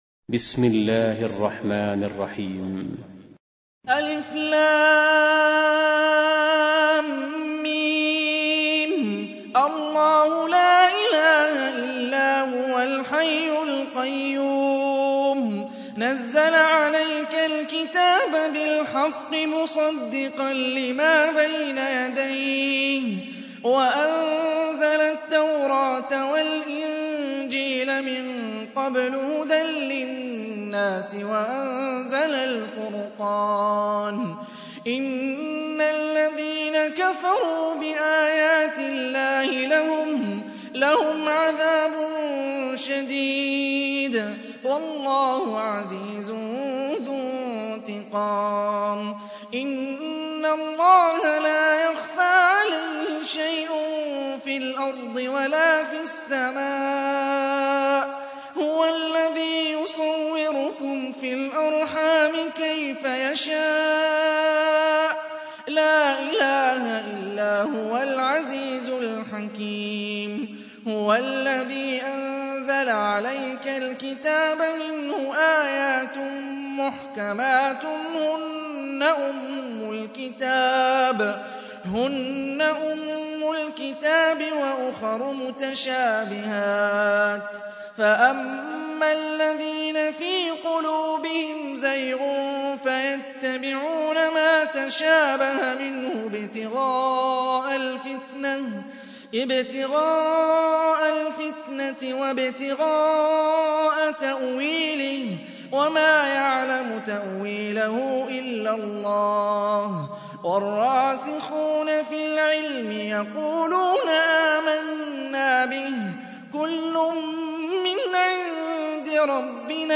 Audio Quran Tarteel Recitation
حفص عن عاصم Hafs for Assem
Recitations with Sheikh Hani Ar-Rifai